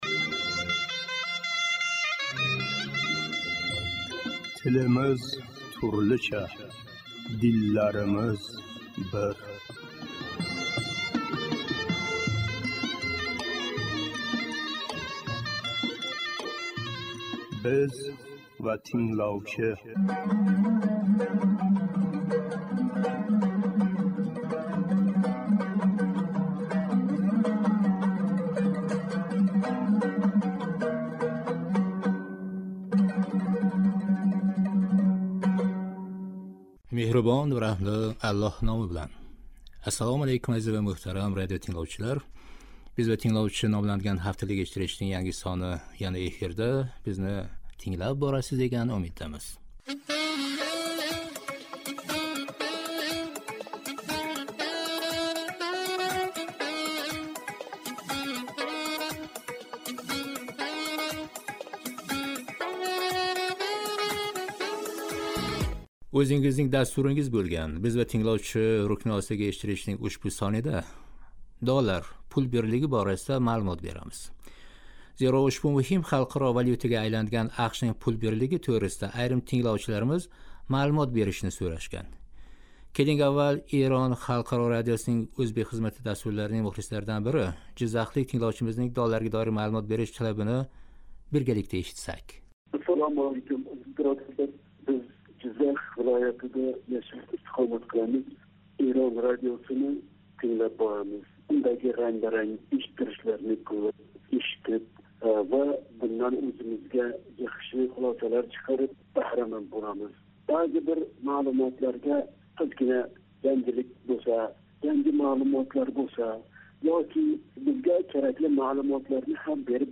"Биз ва тингловчи" номланган ҳафталик эшиттиришнинг янги сони яна эфирда.
Жиззахлик тингловчимизнинг талаби ва суҳбати эди.